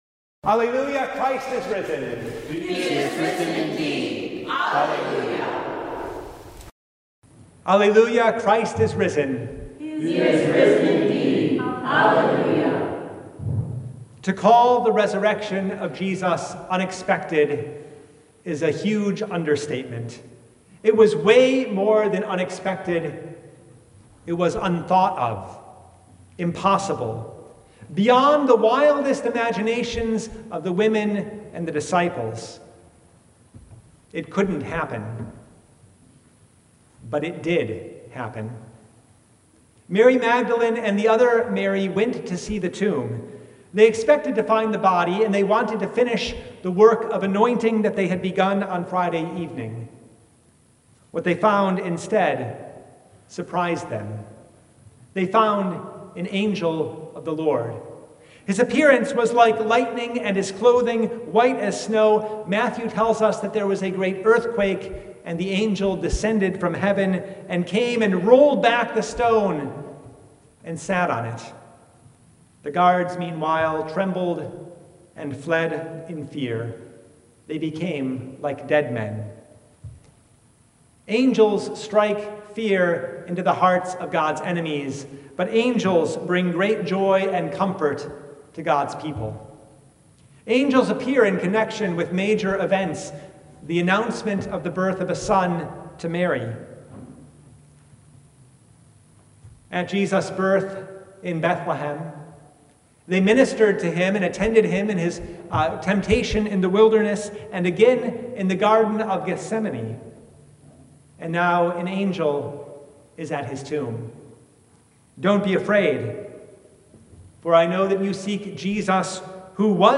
Preacher: